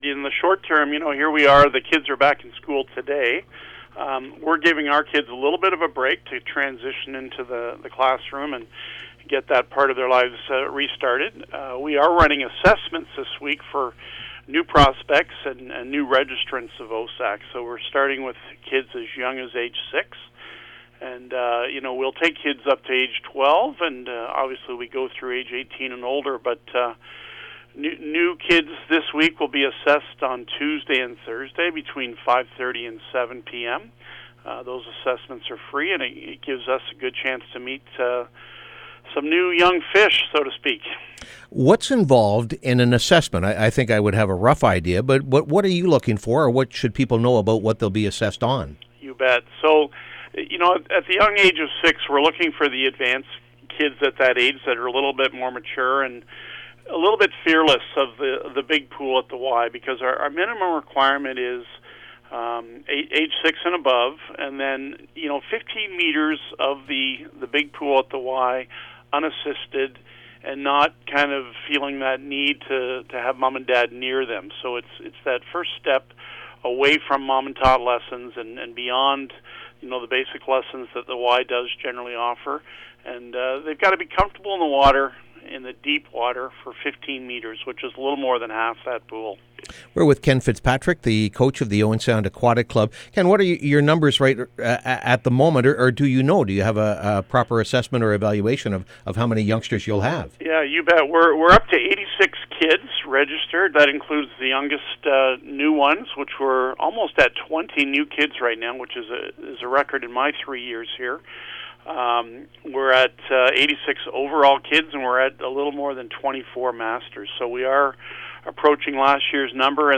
Back To School, Back To Pool. Interview